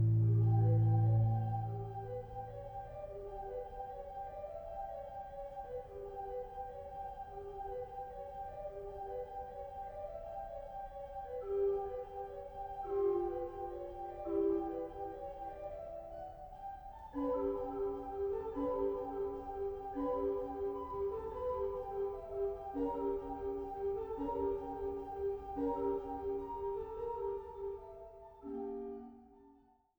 Stellwagen-Orgel